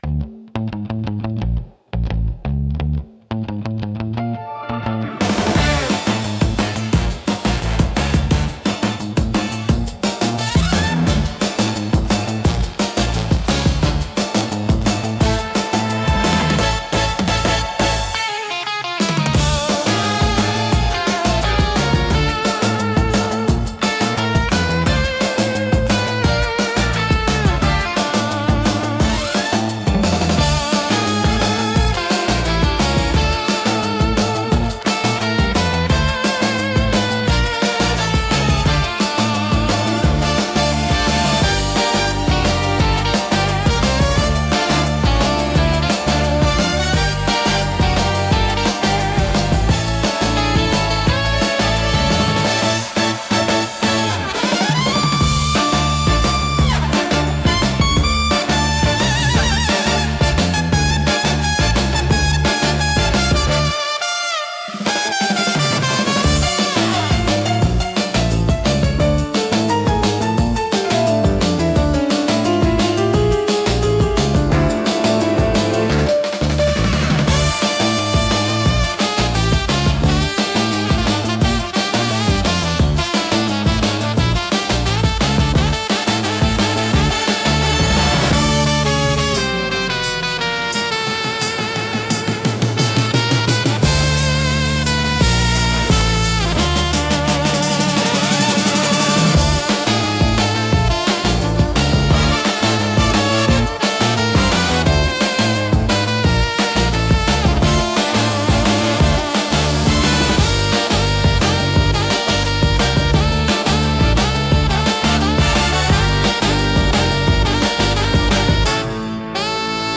不穏さとエネルギーが同居し、聴く人の集中力を高めつつドキドキ感を作り出します。